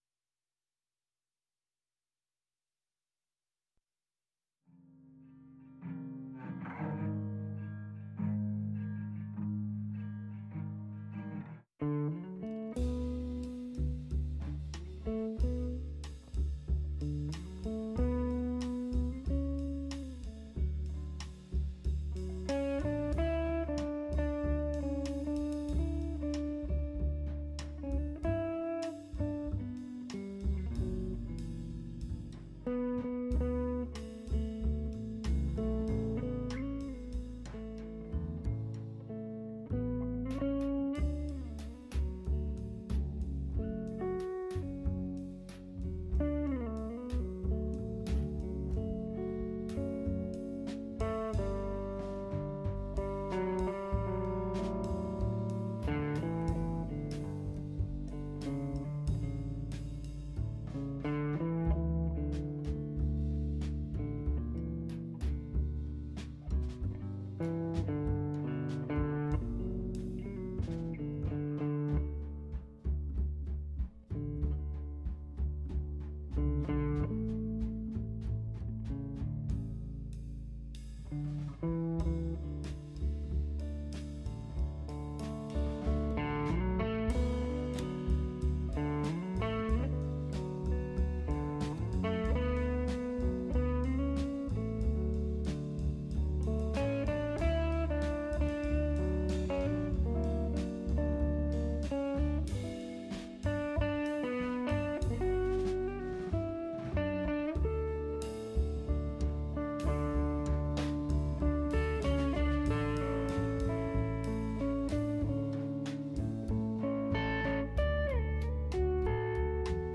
Right here on KDRT 95.7FM in Davis, California. Listening Lyrics is a genre free zone - we feature the artist.
Listen to what only commercial free community radio can bring you.